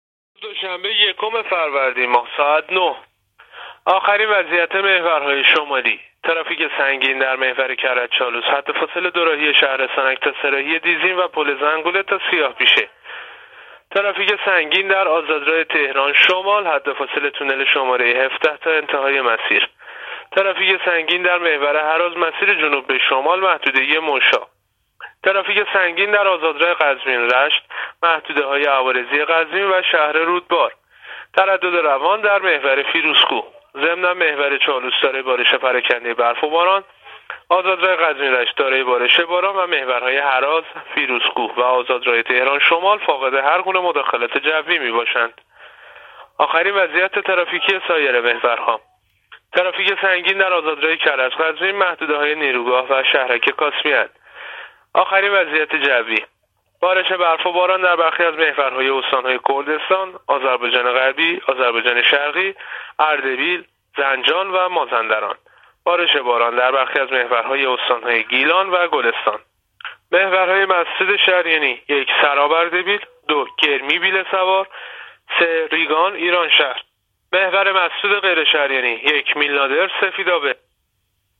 گزارش رادیو اینترنتی از آخرین وضعیت ترافیکی جاده‌ها تا ساعت ۹ یکم فروردین ماه؛